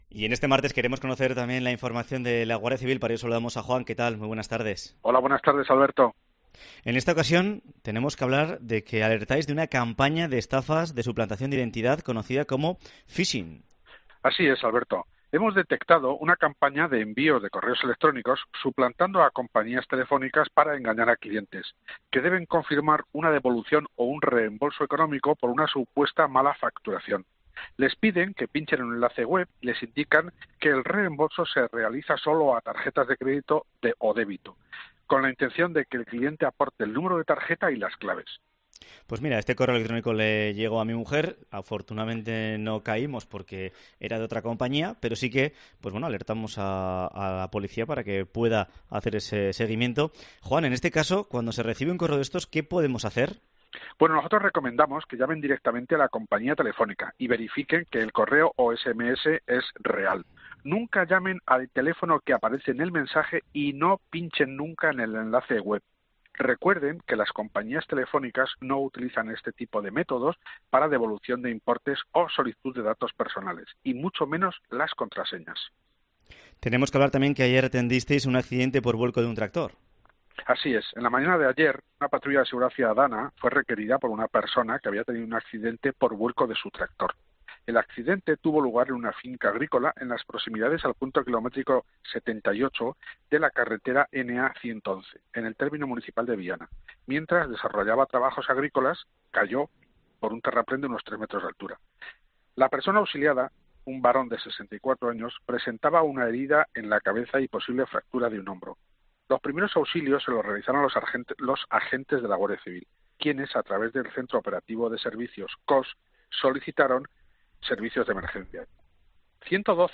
Guardia Civil de Navarra explica el 'phishing'